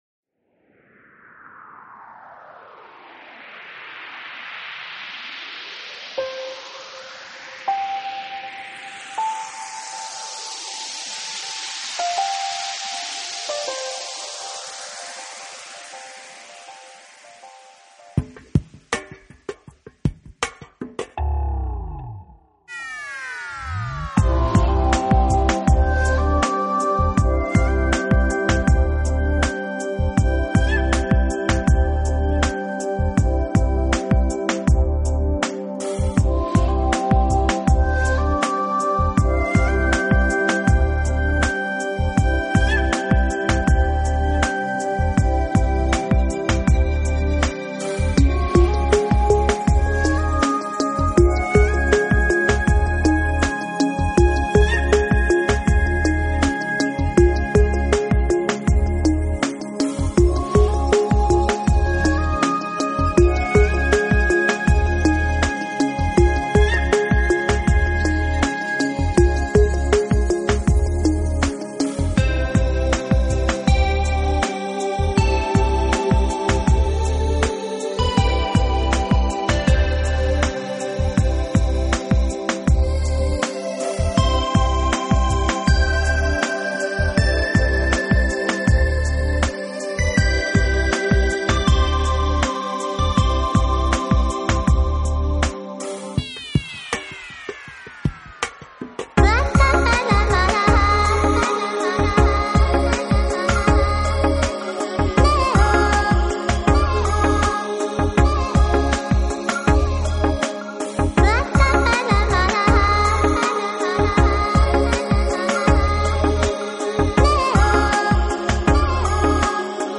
音乐类型：New Age/Ambient/Chillout
众口味，加入了独特的Ambient/Chill Out元素，动感和谐的节奏带给我们一